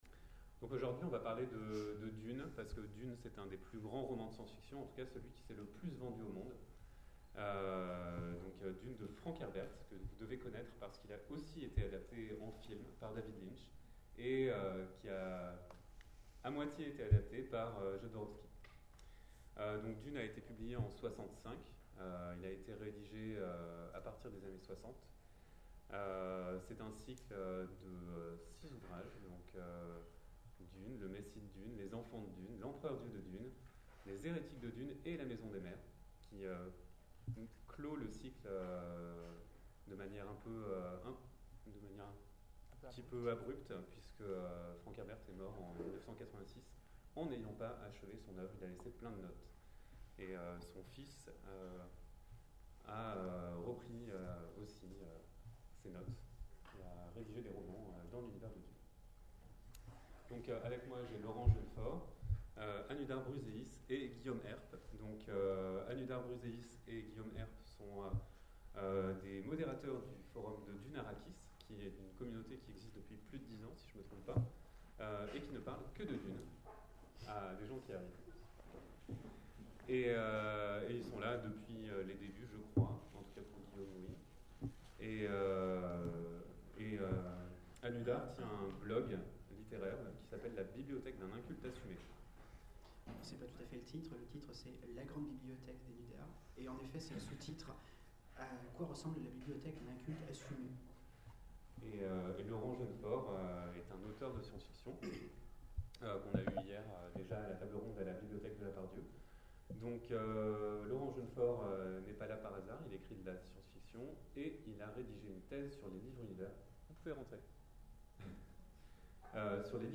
Les intergalactiques 2014 : conférence Dune de Frank Herbert, terre de l’absolu